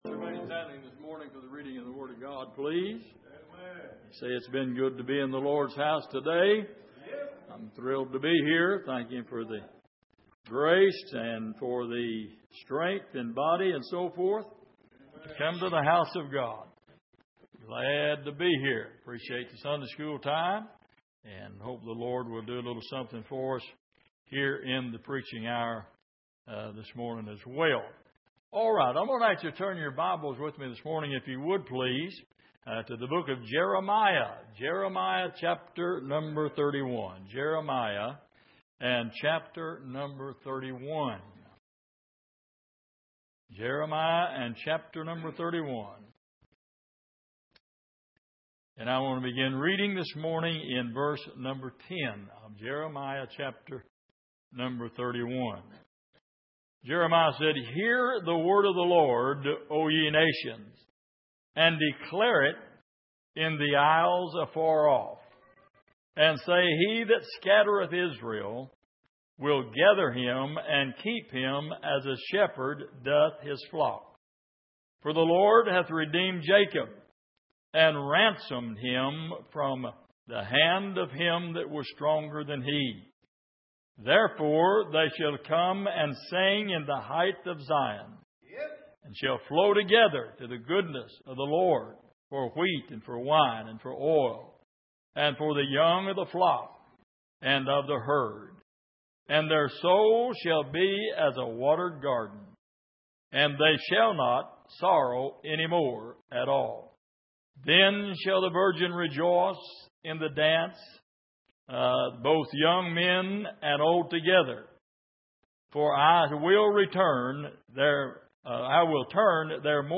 Miscellaneous Passage: Jeremiah 31:10-19 Service: Sunday Morning Are All The Children Home?